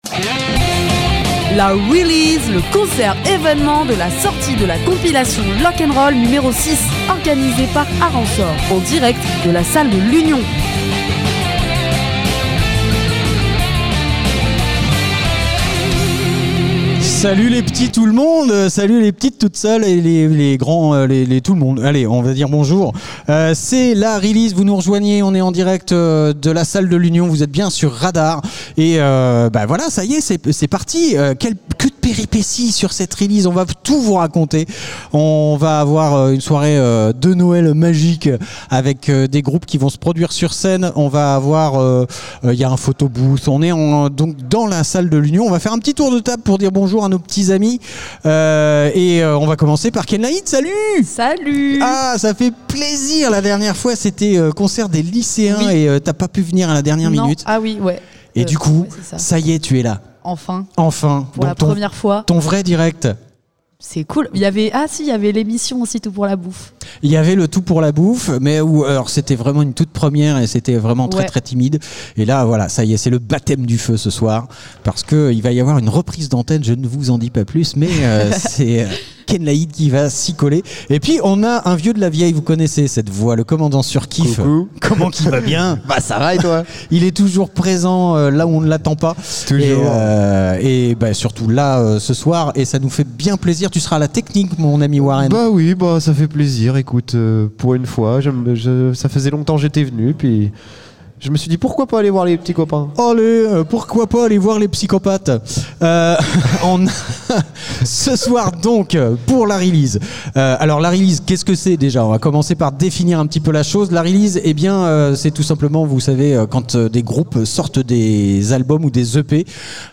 Lors des événements majeurs de l'association, RADAR se doit d'être présent et diffuse les lives agrémentés des interviews des artistes qui s'y produisent.